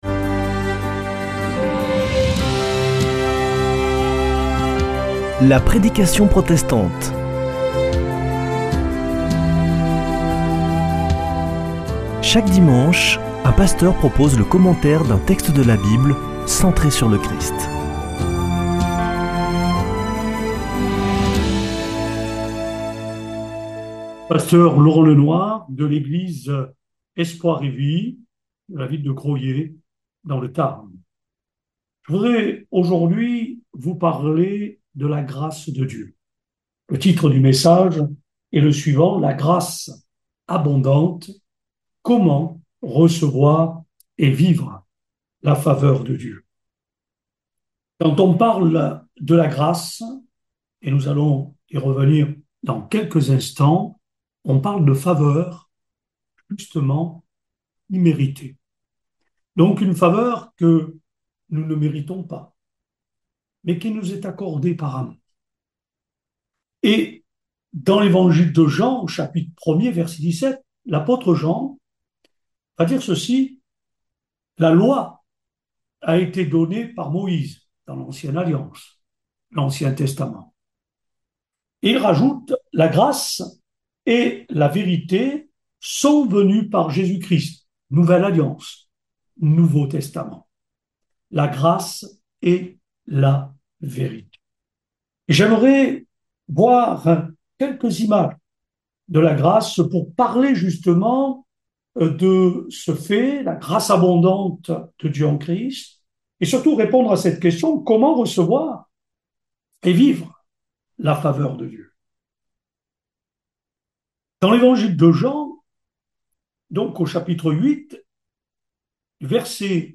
Accueil \ Emissions \ Foi \ Formation \ La prédication protestante \ La grâce abondante : comment recevoir et vivre la faveur de Dieu ?